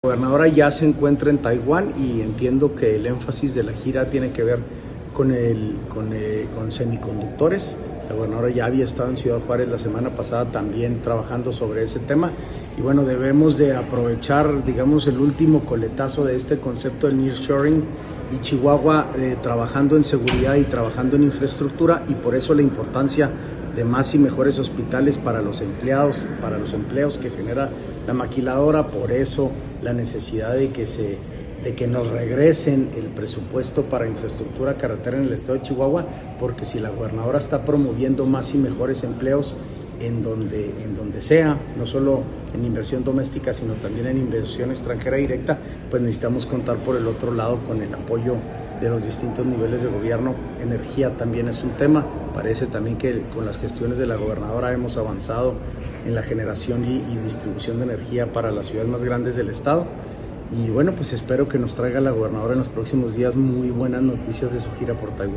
AUDIO: SANTIAGO DE LA PEÑA GRAJEDA, SECRETARIO GENERAL DE GOBIERNO (SGG)